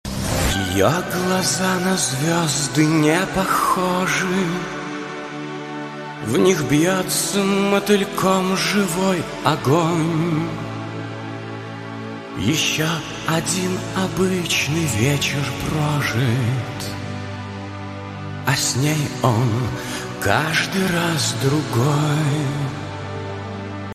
• Качество: 128, Stereo
мелодичные
спокойные
поп-рок